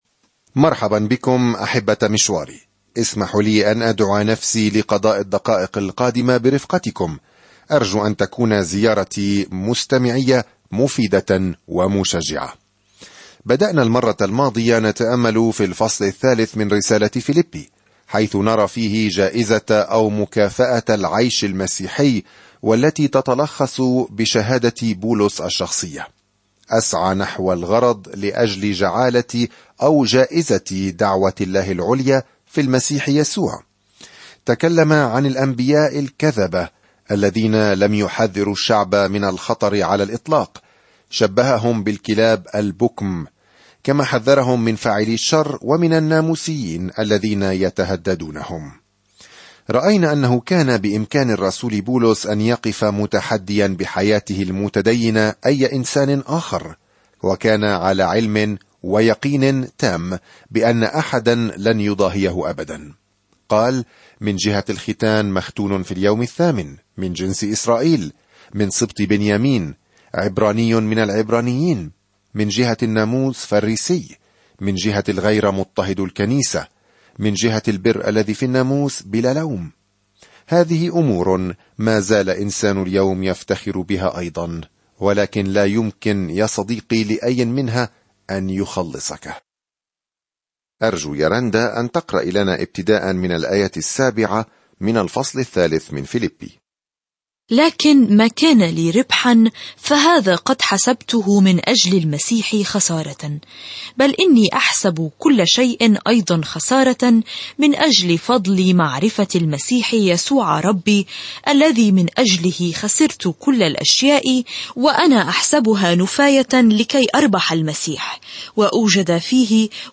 الكلمة فِيلِبِّي 7:3-13 يوم 11 ابدأ هذه الخطة يوم 13 عن هذه الخطة إن رسالة "الشكر" هذه الموجهة إلى أهل فيلبي تمنحهم منظورًا بهيجًا للأوقات الصعبة التي يعيشونها وتشجعهم على اجتيازها بتواضع معًا. سافر يوميًا عبر رسالة فيلبي وأنت تستمع إلى الدراسة الصوتية وتقرأ آيات مختارة من كلمة الله.